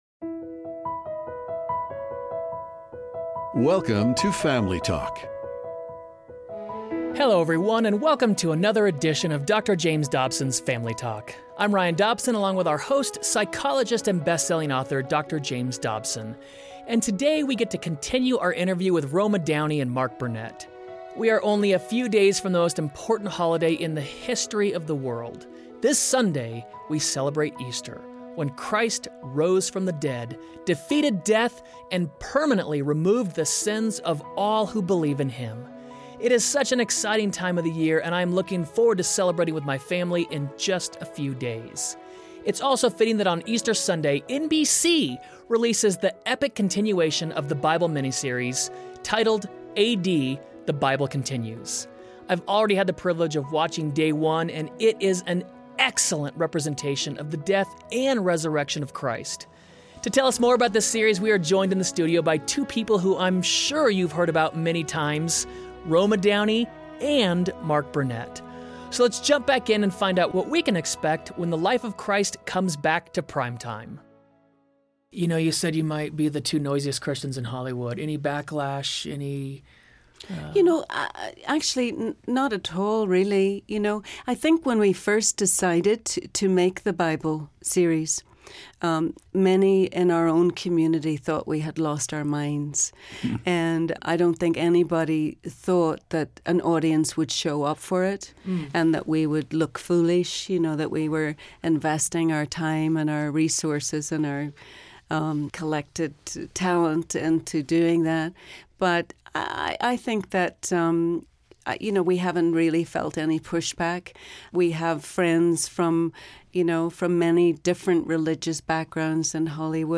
Dr. Dobson welcomes Mark Burnett and Roma Downey, who are back with the powerful continuation of 'The Bible' Mini-series on NBC.